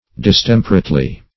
\Dis*tem"per*ate*ly\